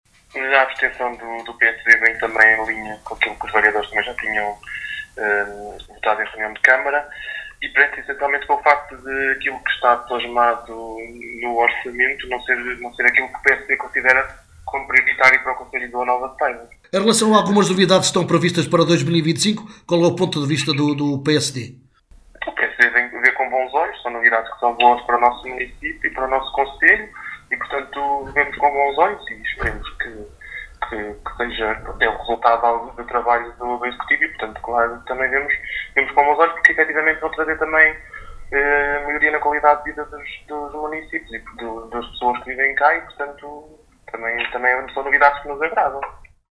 Miguel Almeida, Deputado Municipal do PSD (Partido Social Democrata), referiu a razão da abstenção do seu partido na votação ao Orçamento para 2025.